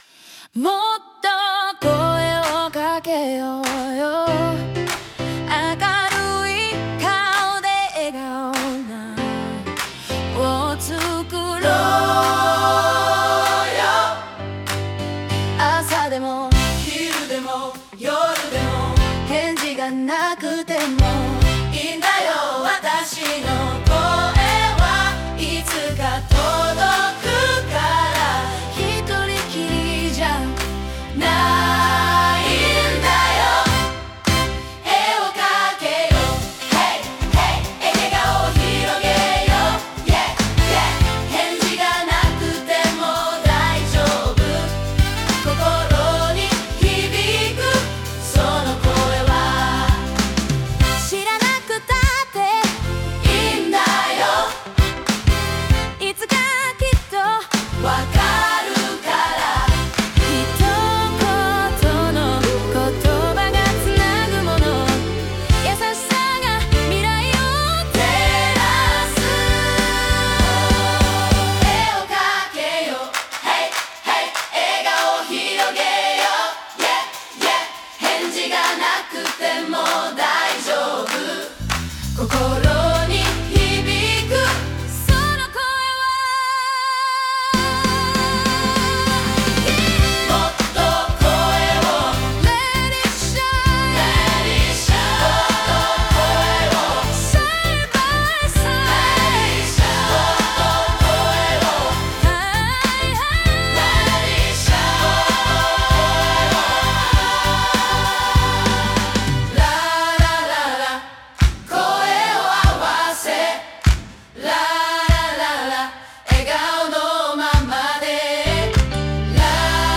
声をかけよう ゴスペル曲 - ライブハウス55 自由が丘